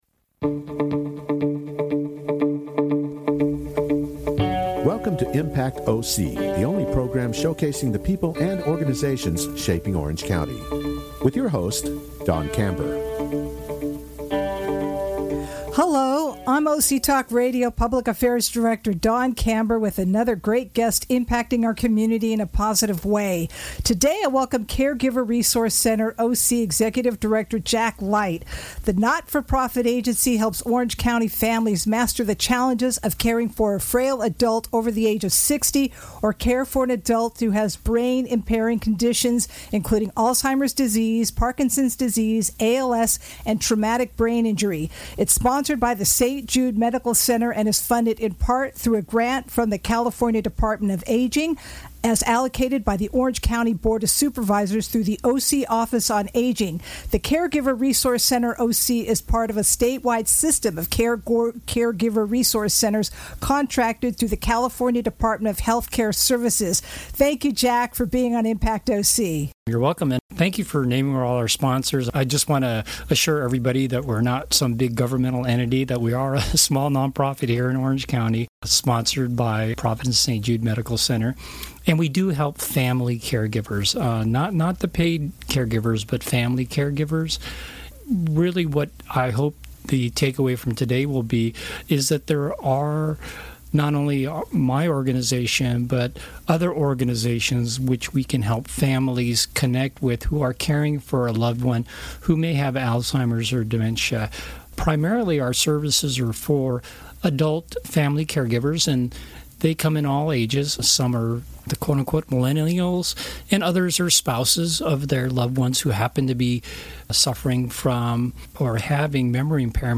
He talks about the free services the organization offers to caregivers. Only on OC Talk Radio, Orange County’s Only Community Radio Station.